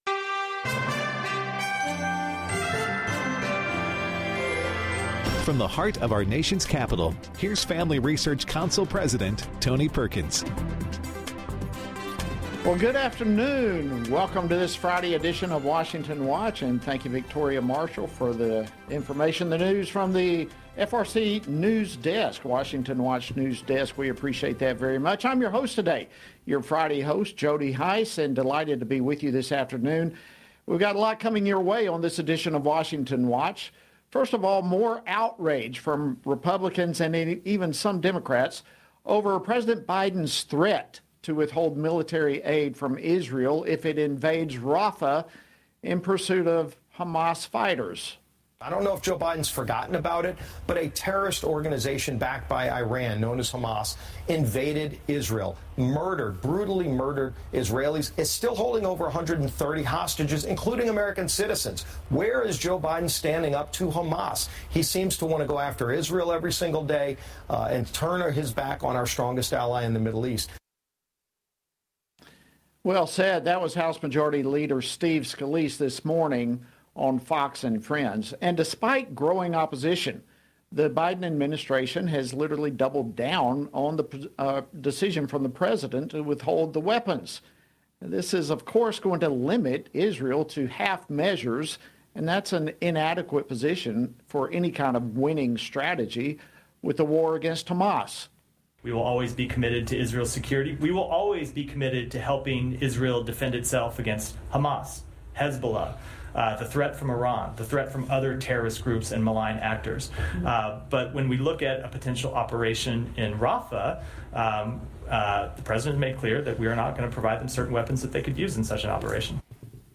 Hard hitting talk radio never has been and never will be supported by the main stream in America!